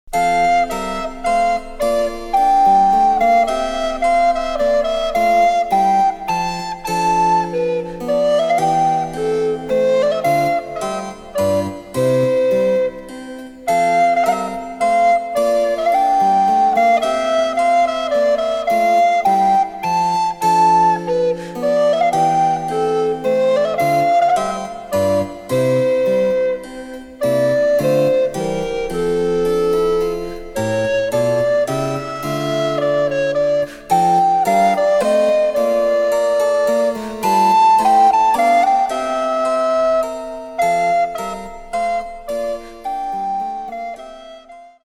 デジタルサンプリング音源使用
・伴奏はモダンピッチのみ。